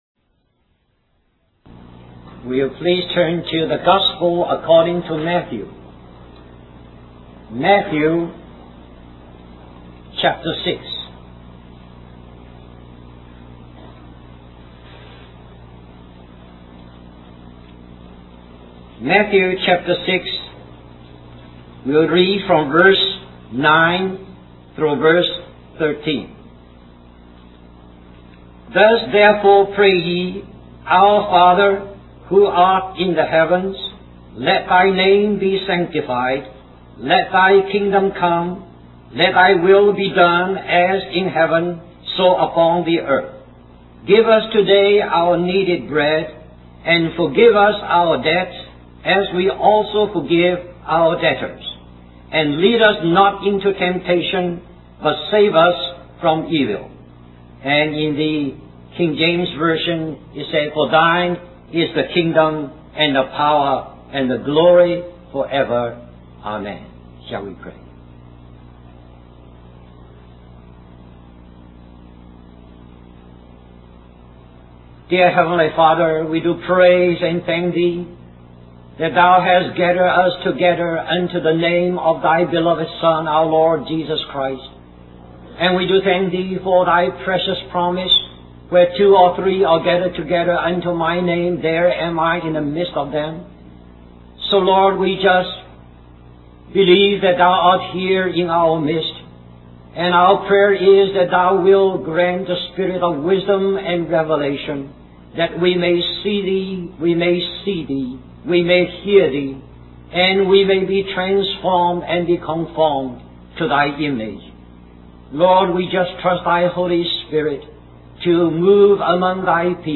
1986 Christian Family Conference Stream or download mp3 Summary This message is also printed in booklet form under the title